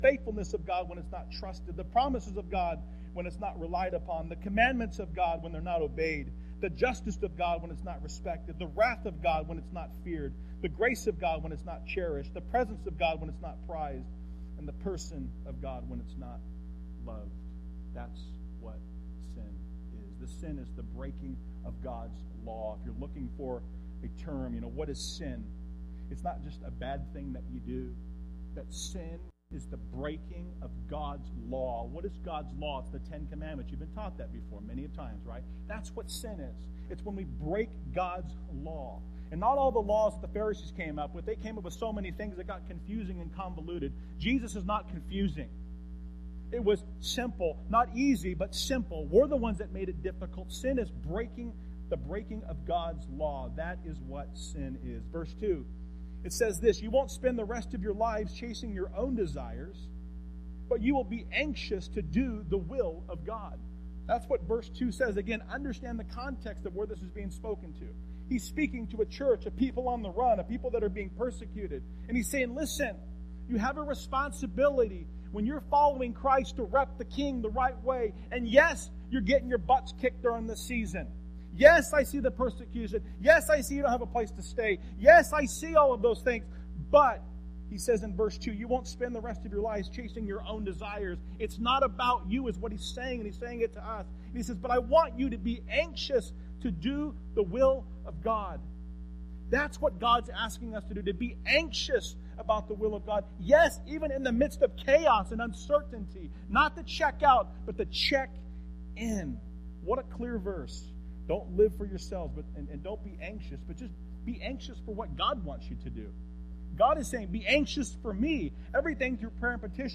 n/a Passage: 1 Peter 4:1-9 Service: Sunday Morning %todo_render% « Let Freedom Ring The Pit